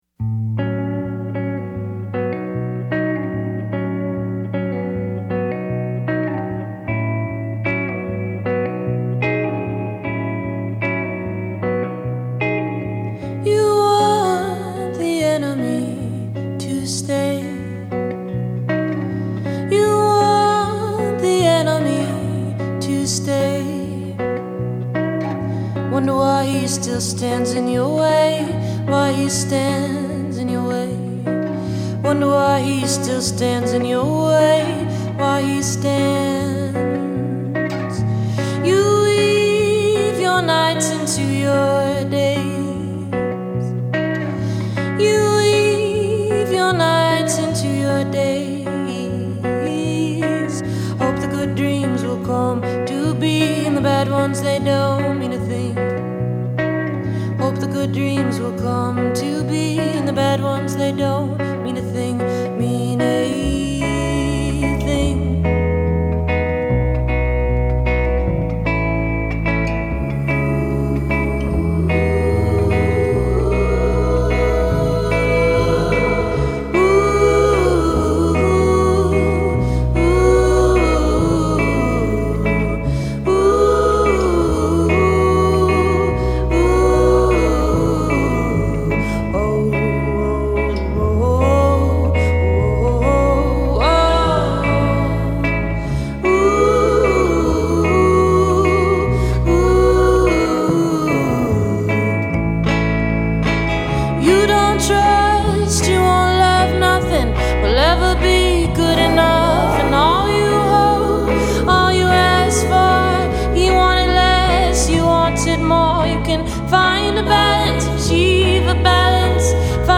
mellow surface masks complex composition